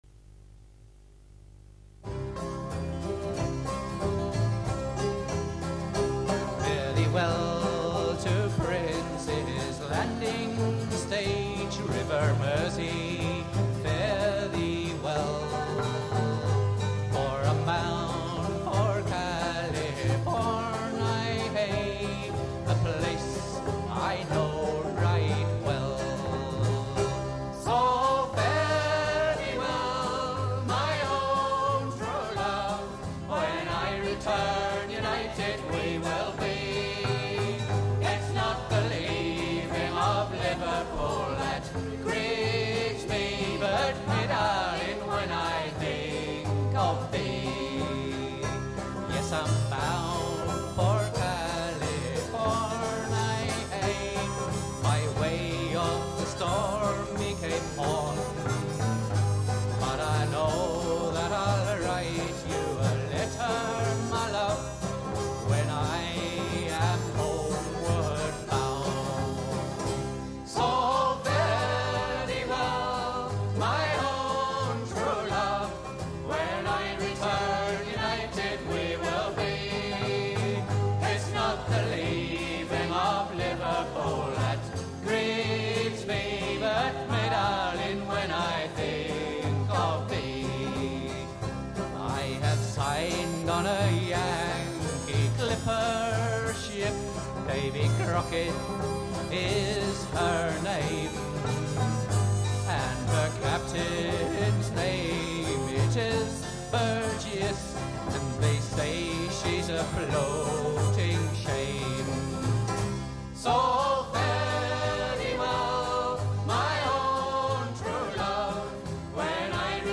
Local musician and performer
Leaving of Liverpool from the album Full Bodied & Fruitly recorded in 1985 at the Greenbank Studios in Luton after a tour of Germany by the band Home Brew.
It is a sailor's song and one of the earliest references was when it was collected from a Liverpool man on board The General Knox in 1885.